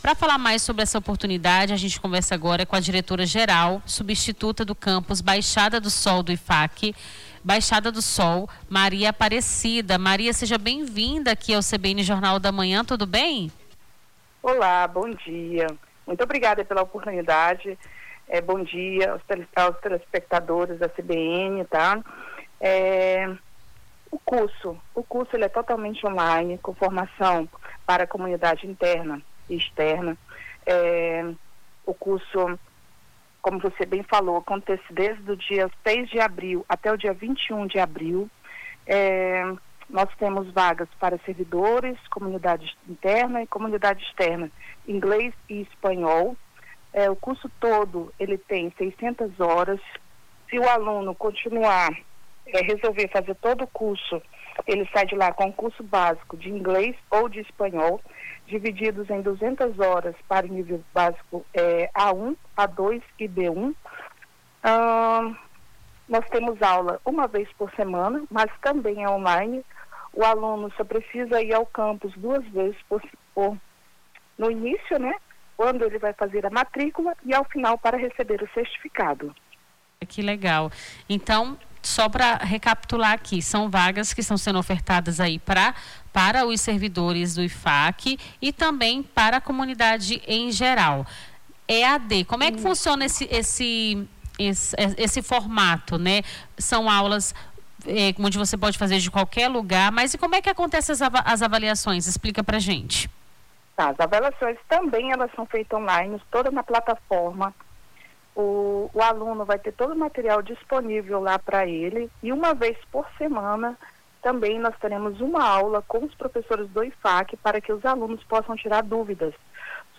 Nome do Artista - CENSURA - ENTREVISTA CURSO IDIOMAS - 10-04-26.mp3